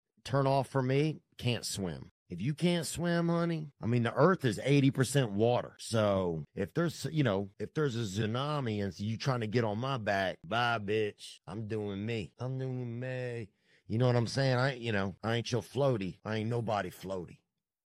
The baby voice makes it funnier… and low-key relatable.